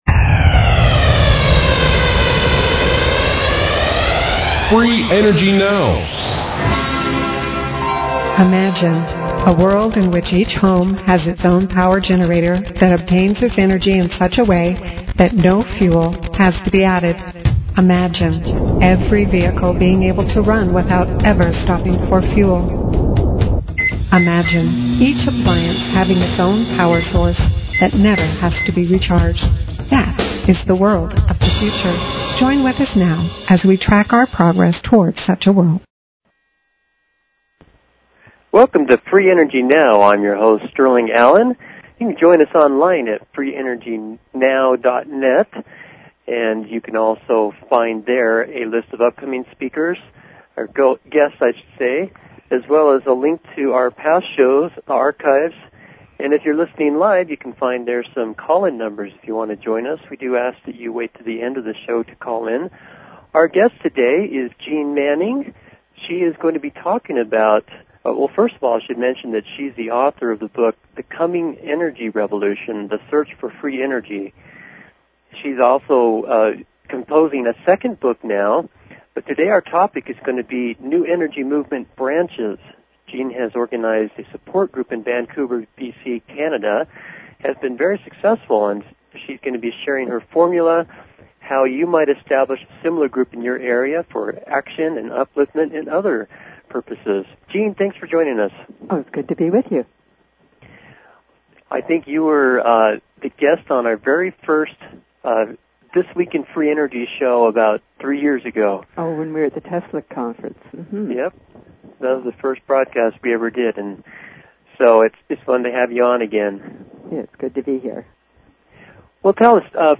Talk Show Episode, Audio Podcast, Free_Energy_Now and Courtesy of BBS Radio on , show guests , about , categorized as